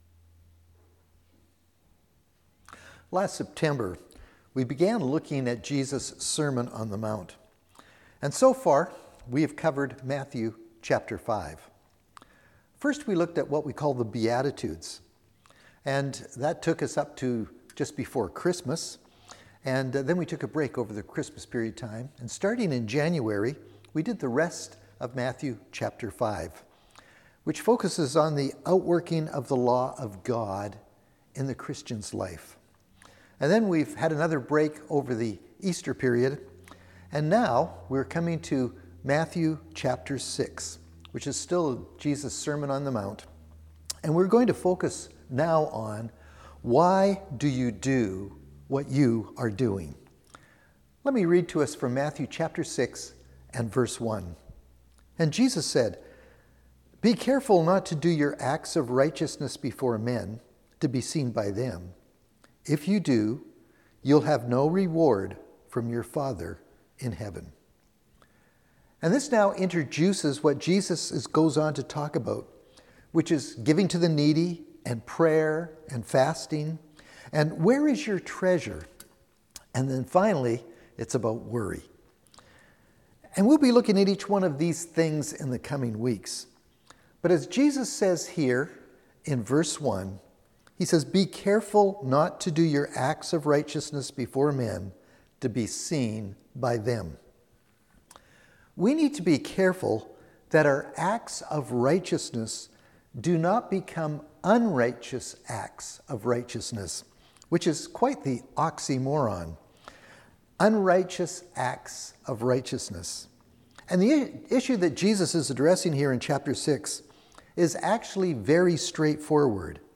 Sermons | Cumberland Community Church